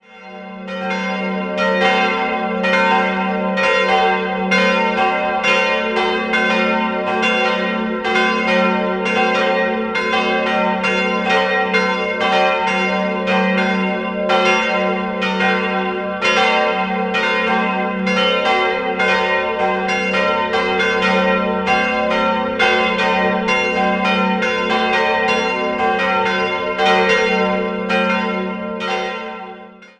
Der neuromanische Turm kam erst 1870 hinzu. 3-stimmiges Geläut: g'-b'-c'' Die Glocken wurden im Jahr 1950 von Karl Czudnochowsky in Erding gegossen.